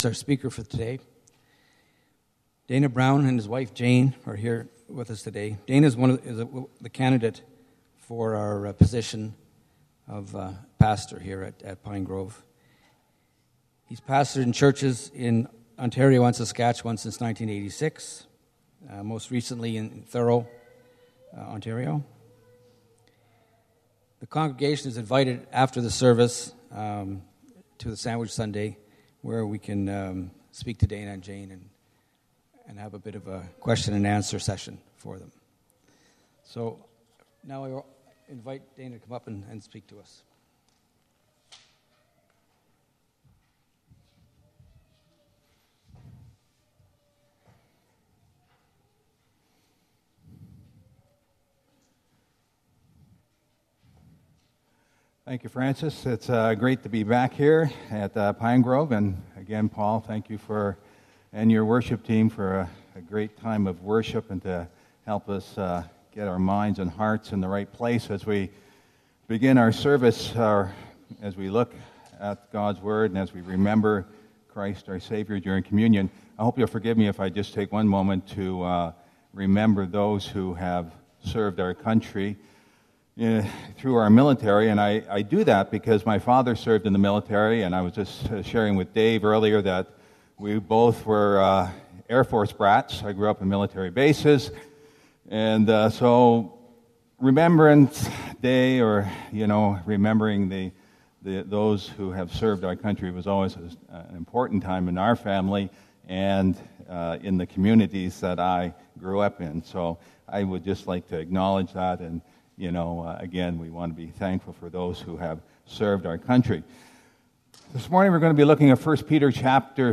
2015 Sermons